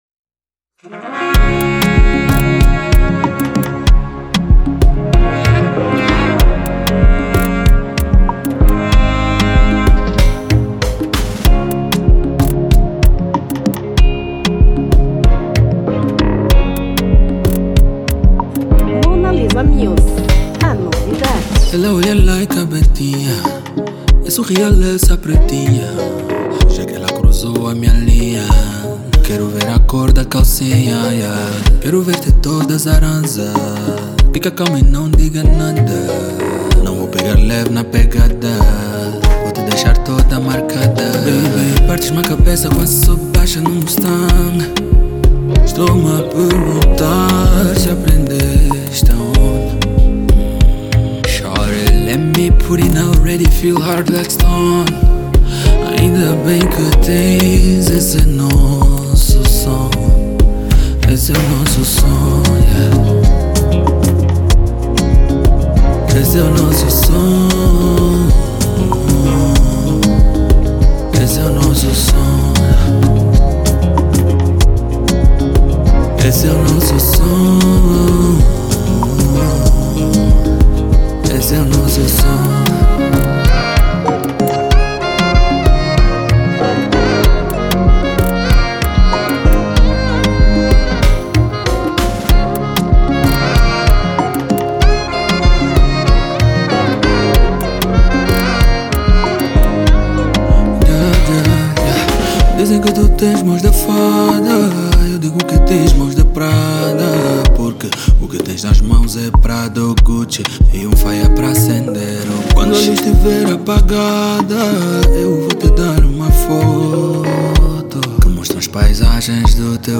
Gênero : Zouk